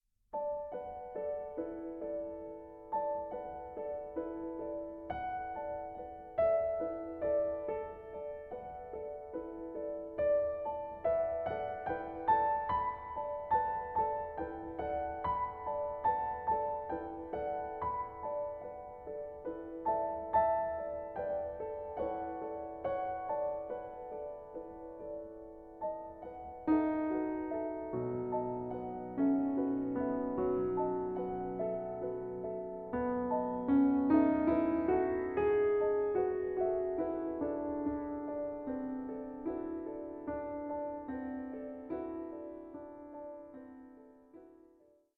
piano (Hamburg Steinway D)
Recorded 11-13 January 2012 at the American Academy of Arts and Letters, New York City, USA